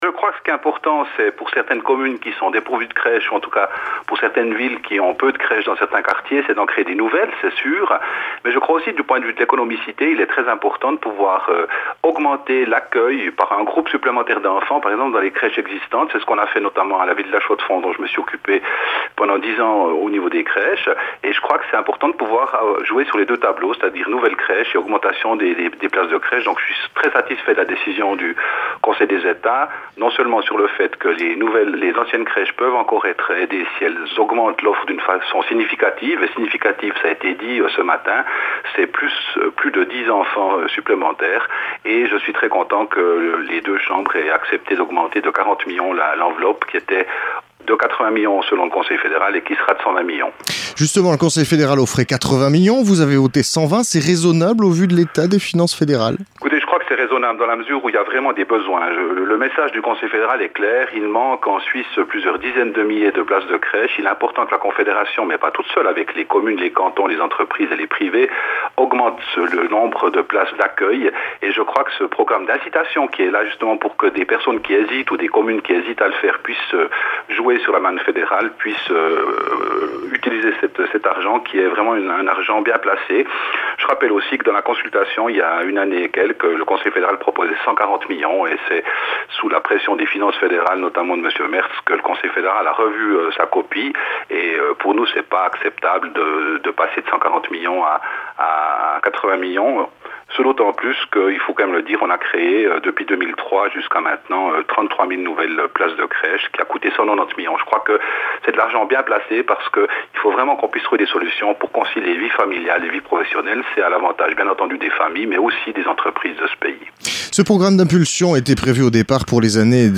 Didier Berberat, socialiste, Conseiller aux Etats du canton de Neuchâtel